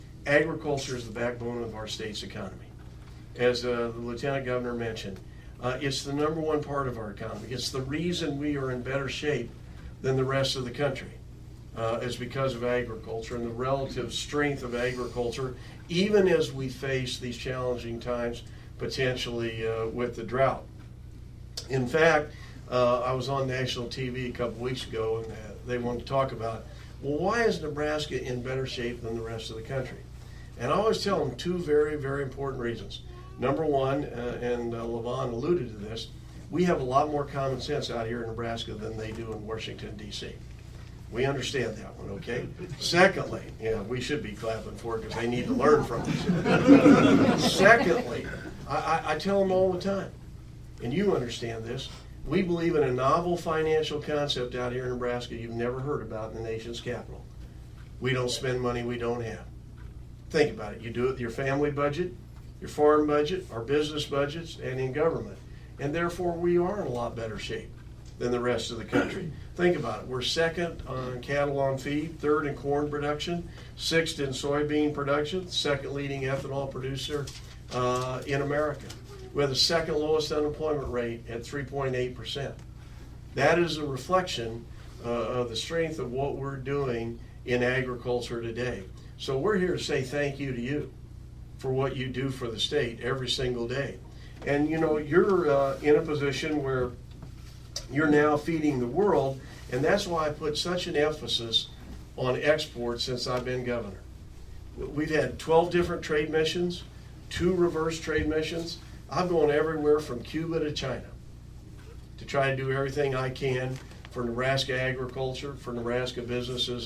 Celebrating National Ag Day 2013 in Nebraska
Listen to the Governor as he talks about Nebraska Agriculture.
The crowds that gathered in Nebraska for Ag Day consisted of FFA students, farmers, college students, agriculture media representatives and ag organizations. Governor Dave Heineman stressed that he is proud of Nebraska agriculture and all that it has done not only for our state but for the world.